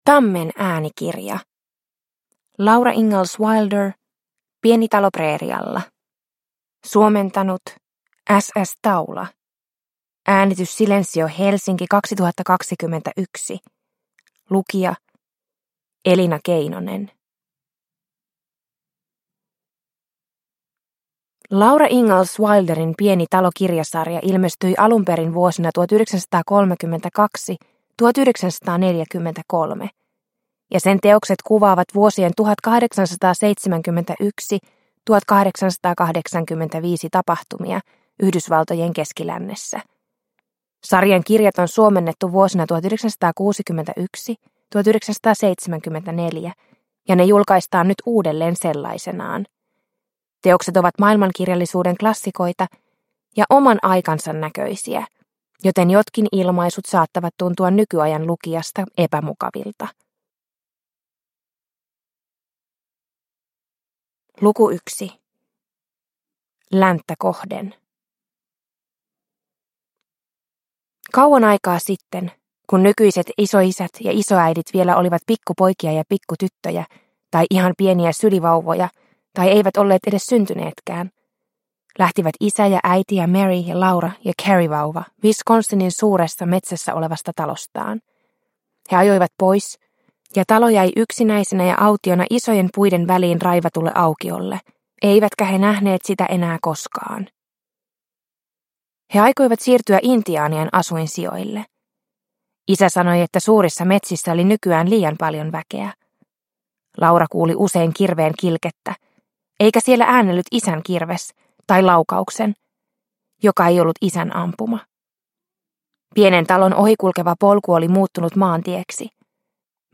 Pieni talo preerialla – Ljudbok – Laddas ner